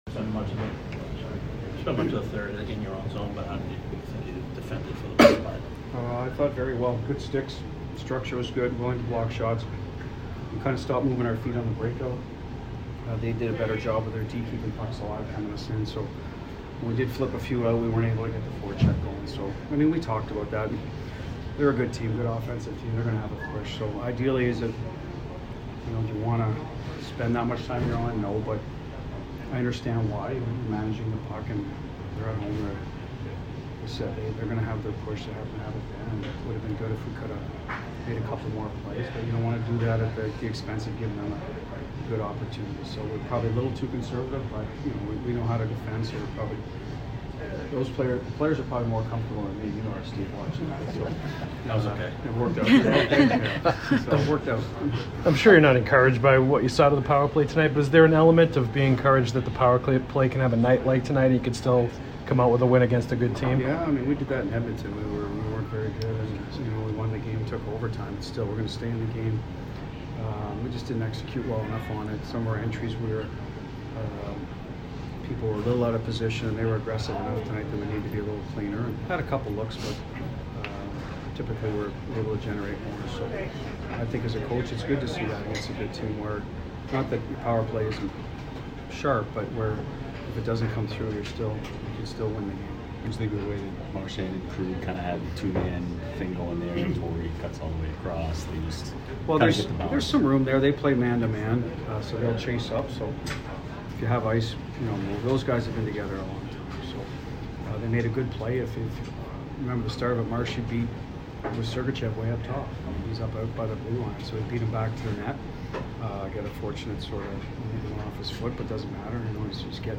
Bruce Cassidy post-game 3/3